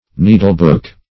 Search Result for " needlebook" : The Collaborative International Dictionary of English v.0.48: Needlebook \Nee"dle*book`\, n. A book-shaped needlecase, having leaves of cloth into which the needles are stuck.